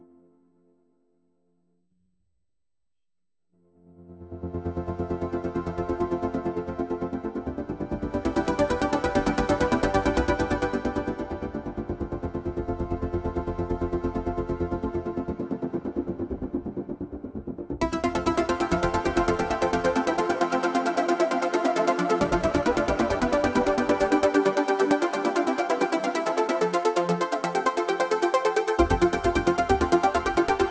A soundtrack techno song trance dance music for the My Talking Baby Meerkat movie.wav